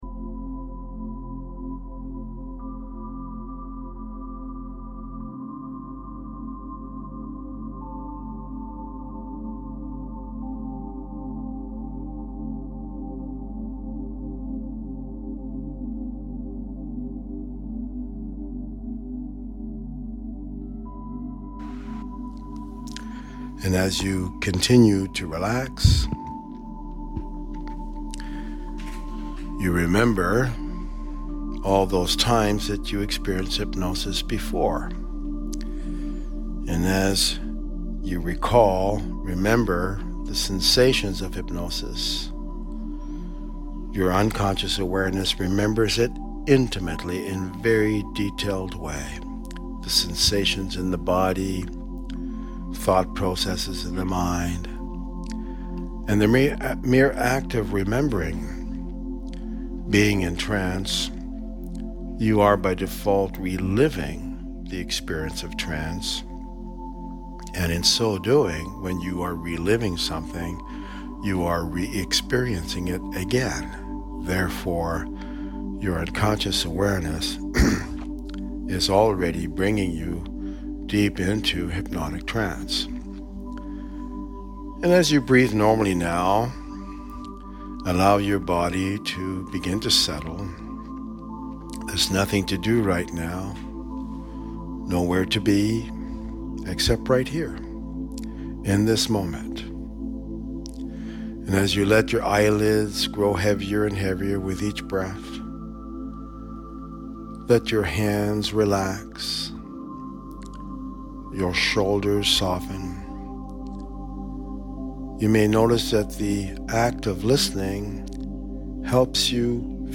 The following is a 27 minute hypnosis recording.
Smoking Relapse Resolved Theta.mp3